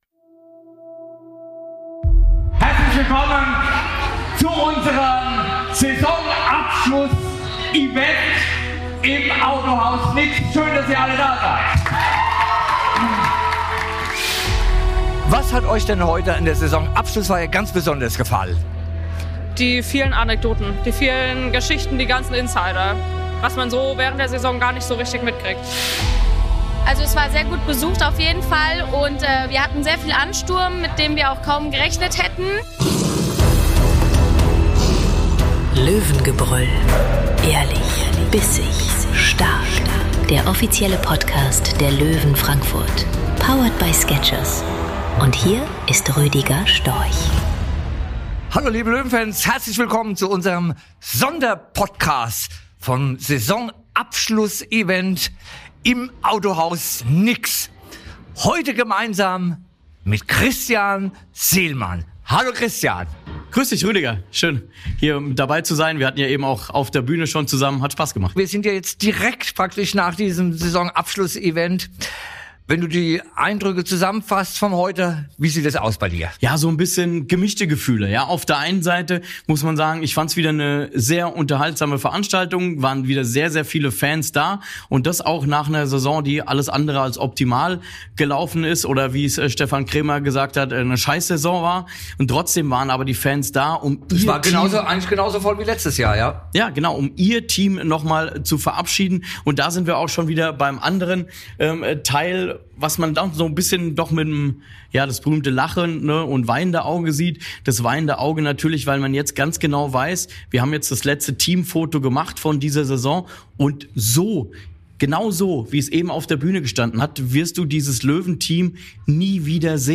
Trotz einer Spielzeit, die alles andere als optimal lief, ist die Halle voll.
Zwischen Autogrammstunde, Fan-Stimmen und vielen kleinen Geschichten zeigt sich, was diesen Club ausmacht: Leidenschaft, Zusammenhalt und eine Fangemeinschaft, die auch nach einer schwierigen Saison da ist.